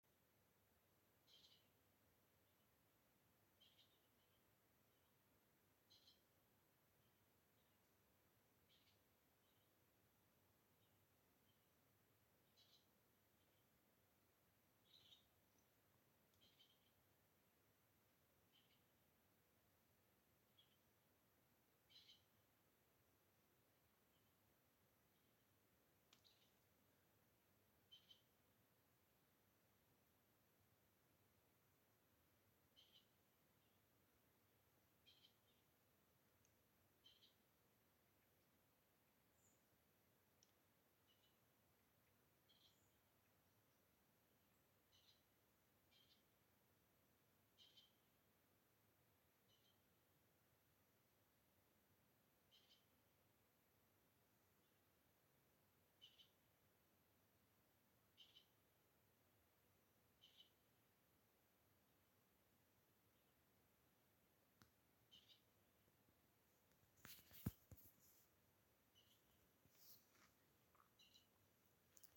Grey Partridge, Perdix perdix
StatusVoice, calls heard